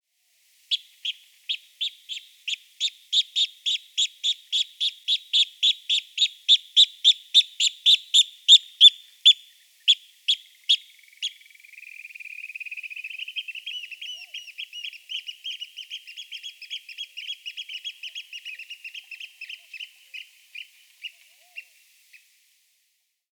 Austernfischer
Hören Sie sich hier die Stimme des Austernfischers an:
Erregungsrufe der Austernfischer
196-austernfischer_erregungsrufe-soundarchiv.com_.mp3